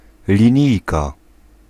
Ääntäminen
France: IPA: [ʁɛɡl]